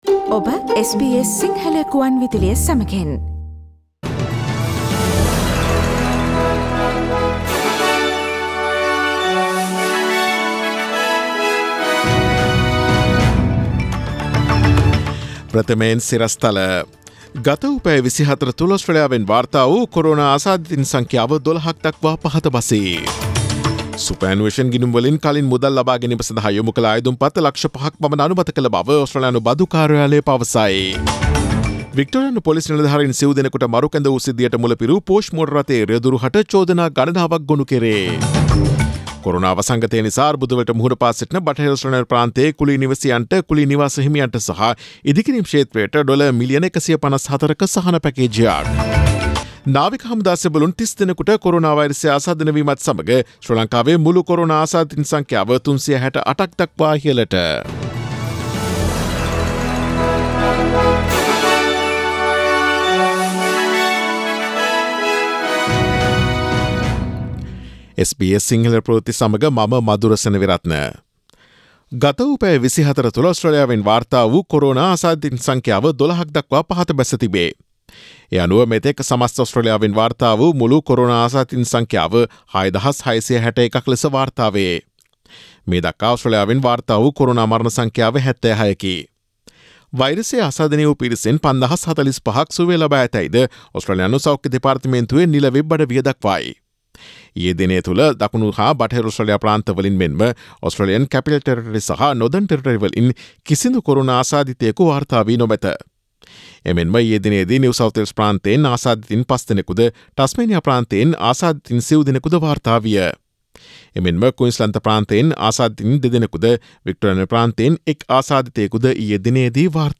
Daily News bulletin of SBS Sinhala Service: Friday 24 April 2020